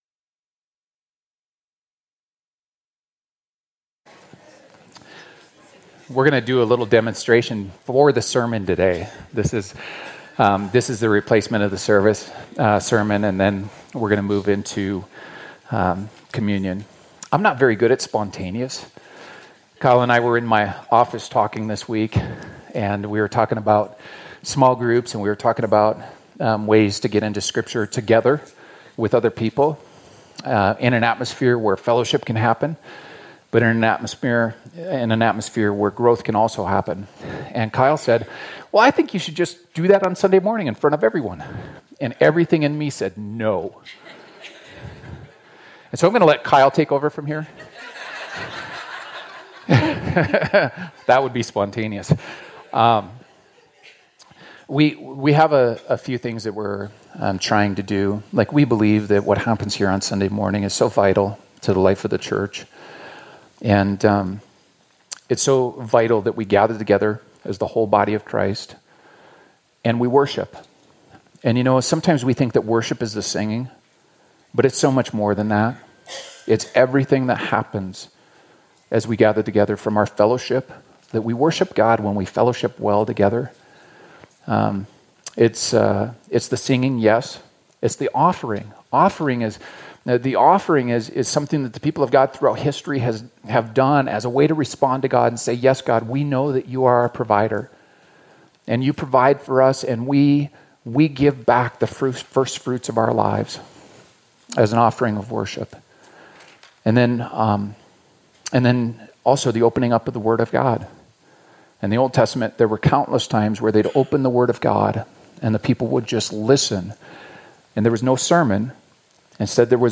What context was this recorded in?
Today, we are doing interactive Bible study time, followed by communion at the end of the service. Communion reminds us of Jesus's sacrificial death on the cross.